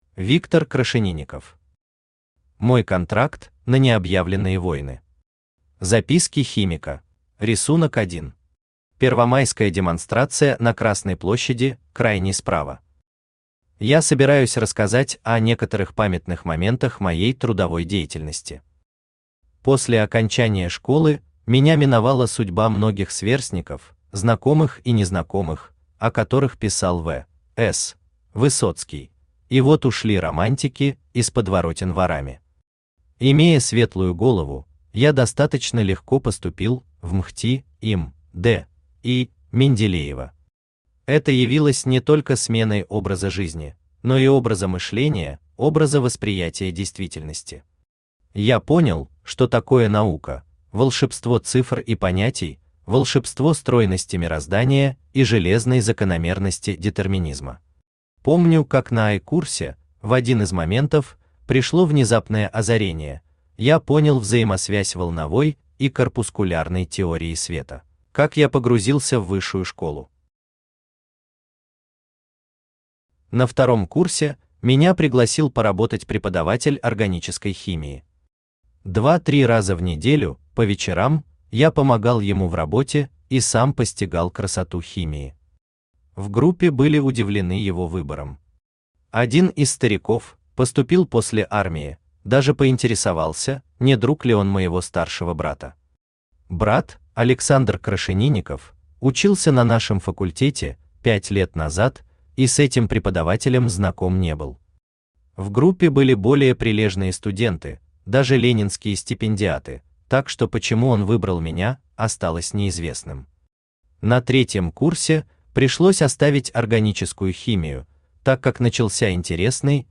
Аудиокнига Мой контракт на необъявленные войны. Записки химика | Библиотека аудиокниг
Записки химика Автор Виктор Иванович Крашенинников Читает аудиокнигу Авточтец ЛитРес.